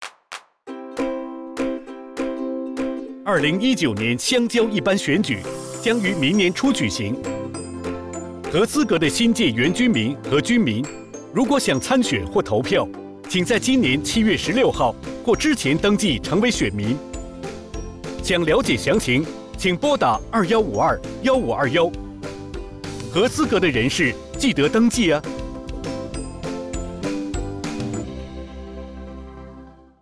电台宣传声带